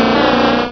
Cri d'Excelangue dans Pokémon Rubis et Saphir.